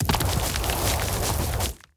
Rock Meteor Swarm 1.wav